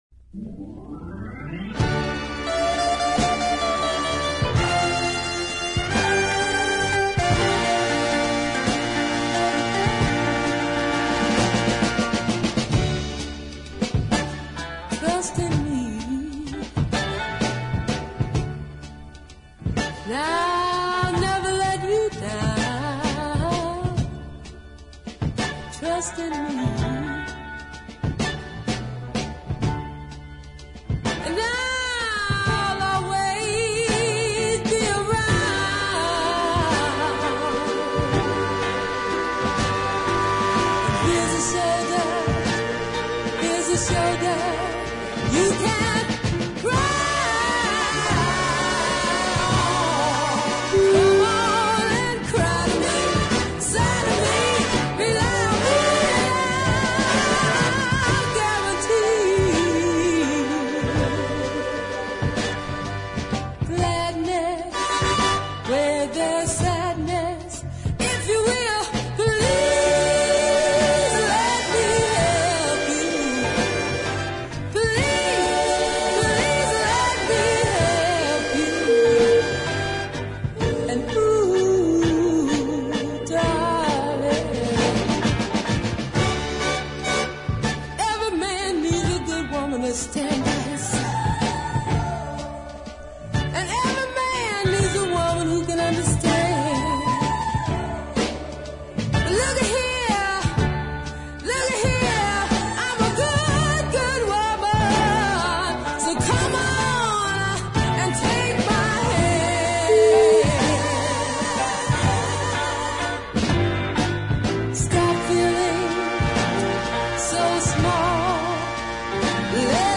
deep soul masterpiece